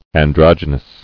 [an·drog·y·nous]